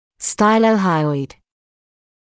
[ˌstaɪləu’haɪɔɪd][ˌстайлоу’хайойд]шилоподъязычный